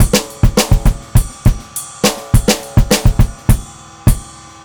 VOS BEAT 1-L.wav